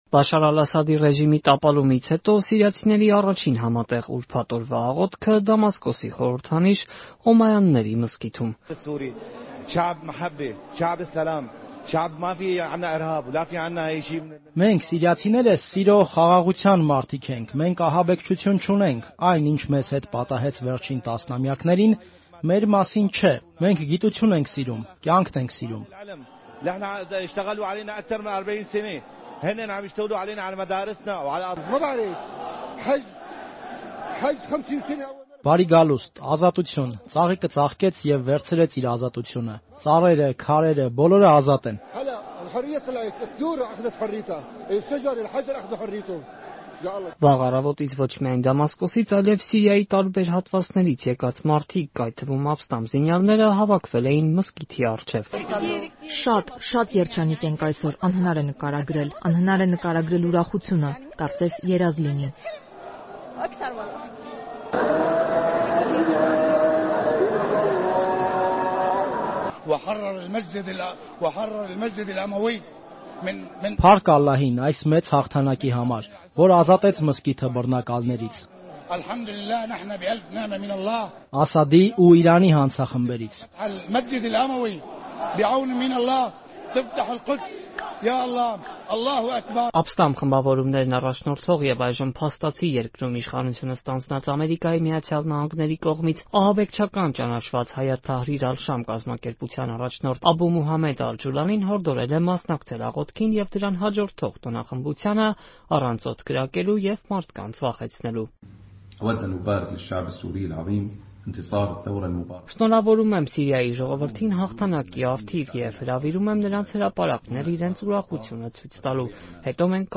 Ասադի տապալումից հետո սիրիացիների առաջին համատեղ աղոթքը՝ Օմայանների մզկիթում
Ռեպորտաժներ